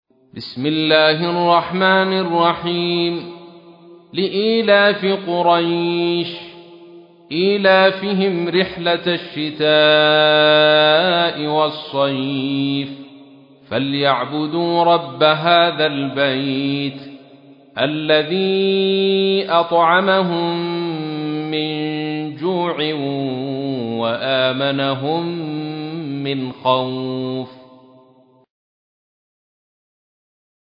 تحميل : 106. سورة قريش / القارئ عبد الرشيد صوفي / القرآن الكريم / موقع يا حسين